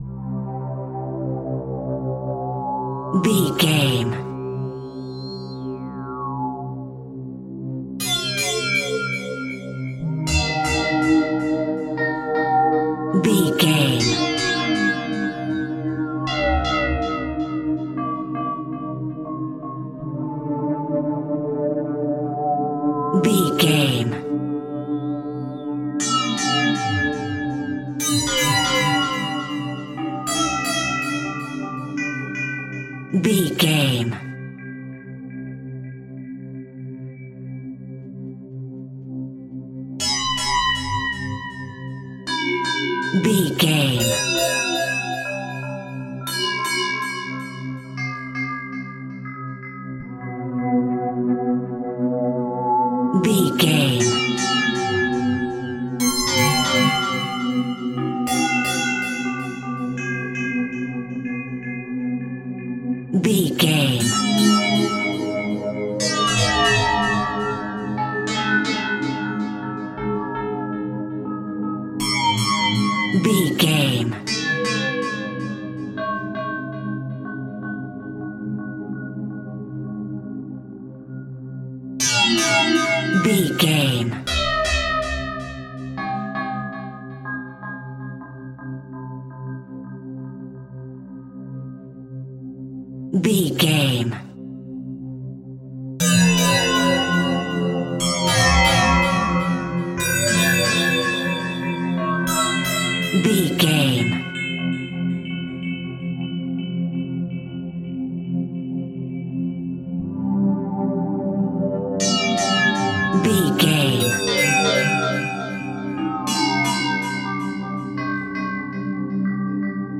Atonal
Slow
scary
tension
ominous
dark
suspense
haunting
eerie
synthesiser
creepy
keyboards
ambience
pads
eletronic